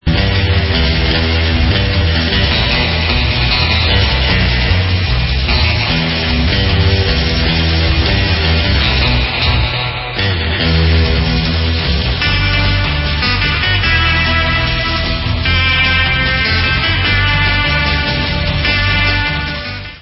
sledovat novinky v oddělení Pop/Instrumental